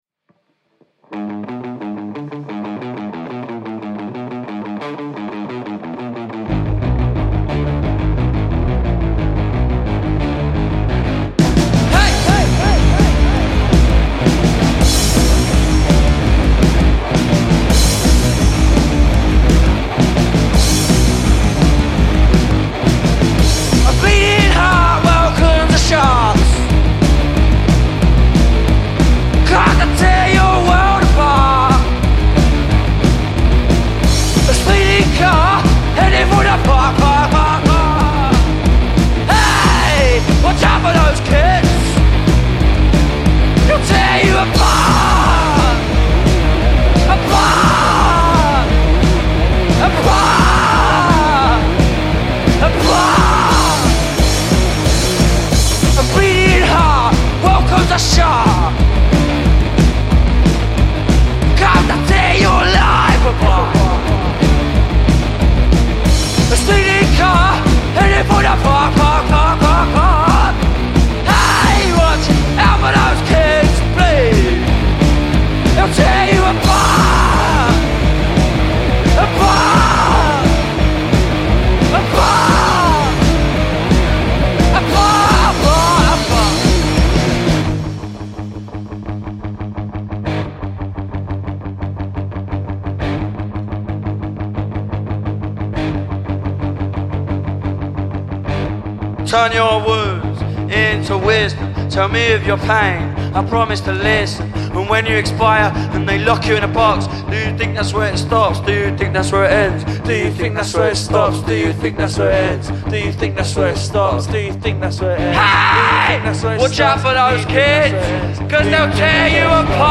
We just had to get them in the studio to perform for us.